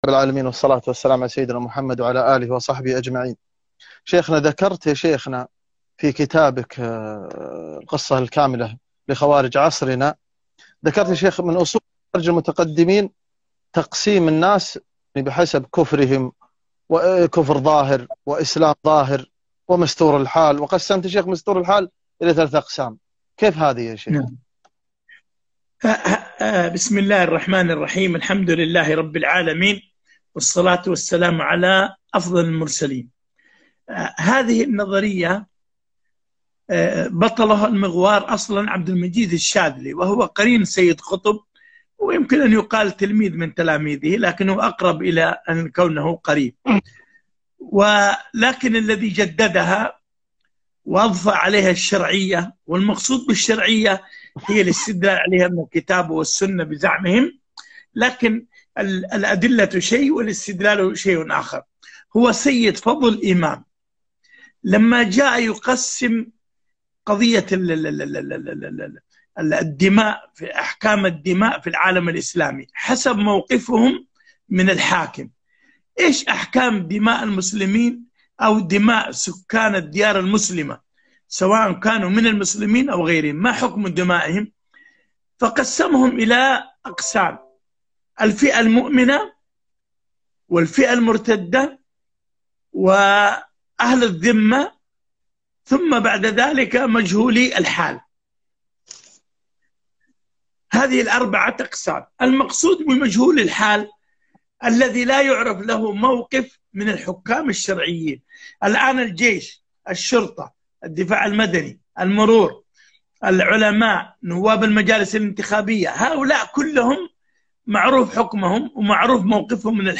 لقاء حول كتاب القصة الكاملة لخوارج عصرنا مع مؤلفه - الجزء الثاني